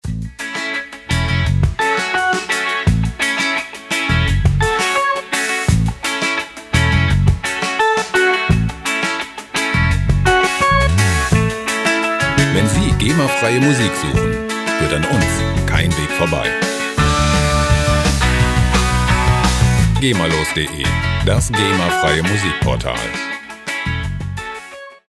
Gema-freie Schlager
Musikstil: Neue Deutsche Welle
Tempo: 85 bpm